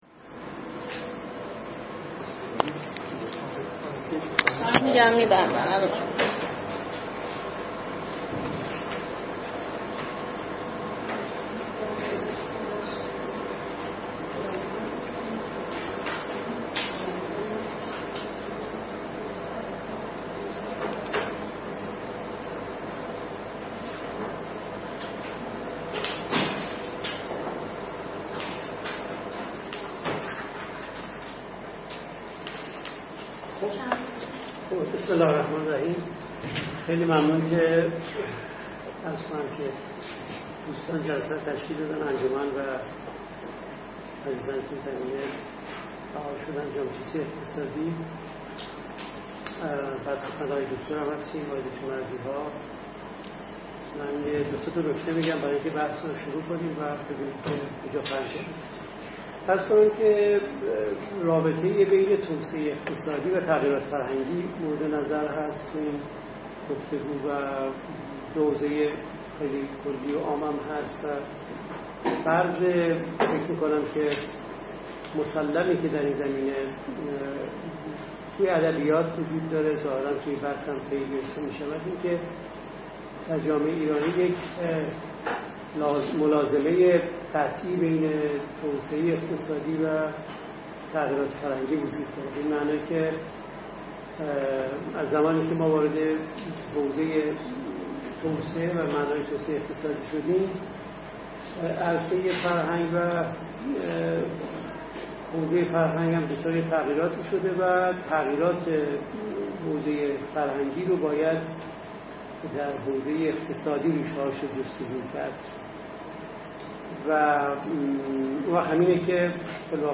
فایل صوتی فوق سخنرانی اساتید ر این نشست است.